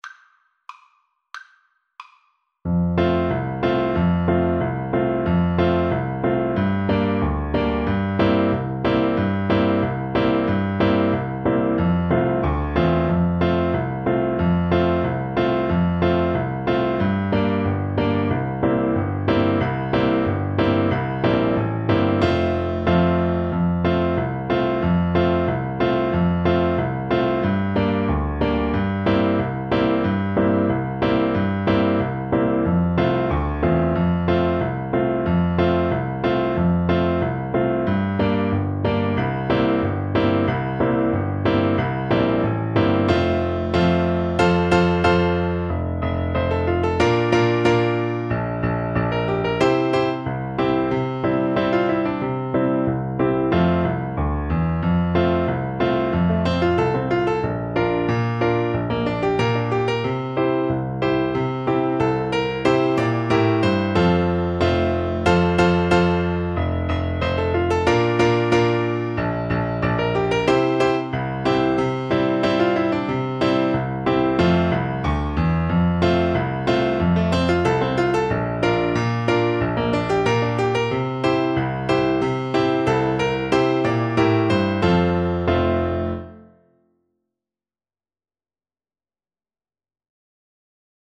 Traditional Music of unknown author.
2/4 (View more 2/4 Music)
Moderato =c.92